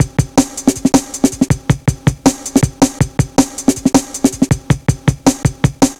Index of /90_sSampleCDs/Zero-G - Total Drum Bass/Drumloops - 1/track 07 (160bpm)